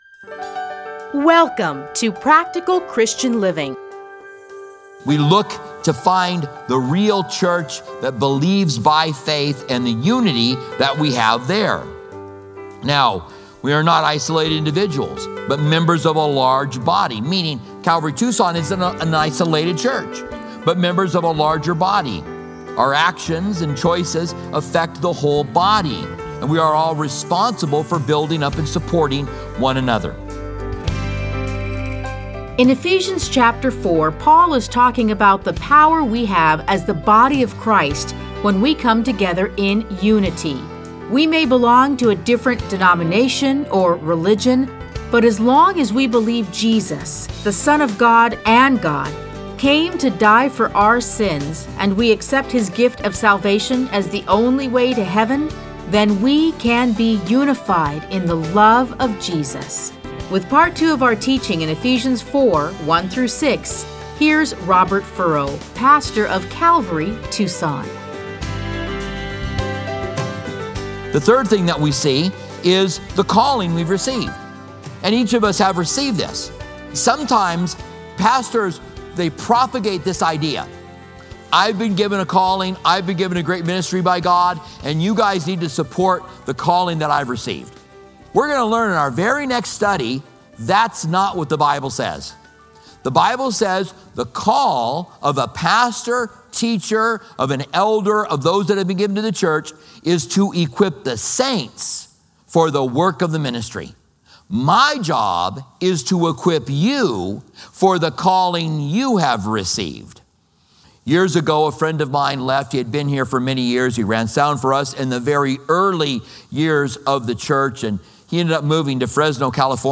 Listen to a teaching from Ephesians 4:1-6.